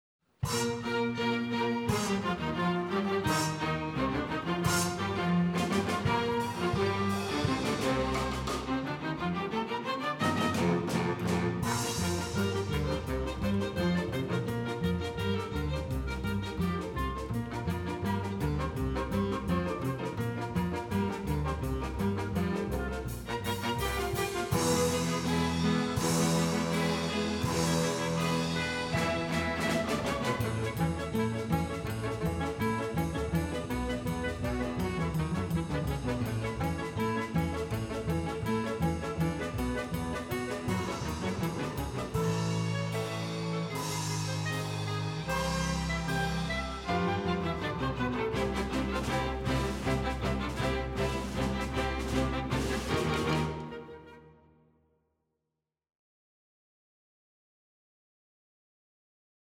Münchner Erstaufführung am 17. Januar 2015 in der Reithalle
Ouvertüre
Musiker des Orchesters des Staatstheaters am Gärtnerplatz